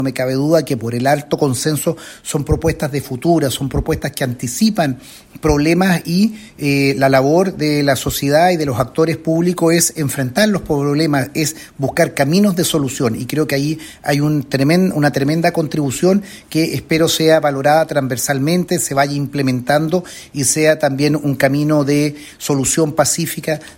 El senador socialista, Alfonso de Urresti, destacó la transversalidad de los acuerdos, esperando que el trabajo de la Comisión Para la Paz y el Entendimiento sea un camino de solución pacífica a los problemas en la Macrozona Sur.